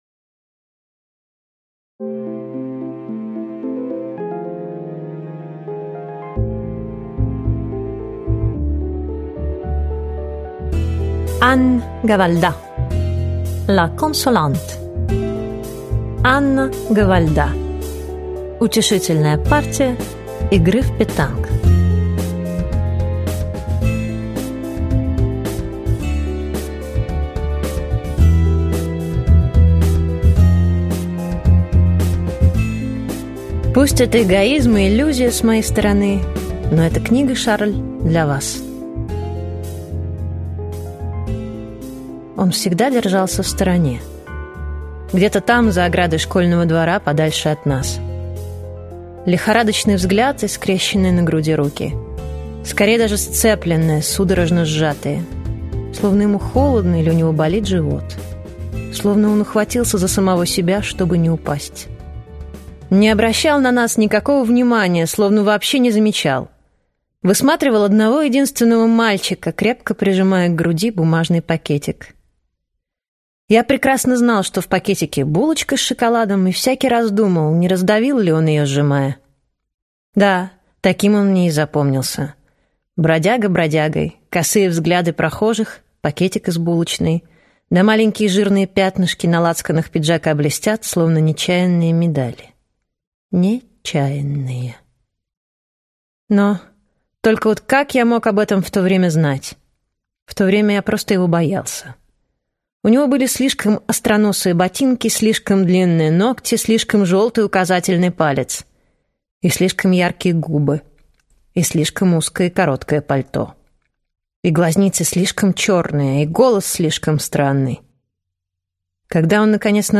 Аудиокнига Утешительная партия игры в петанк | Библиотека аудиокниг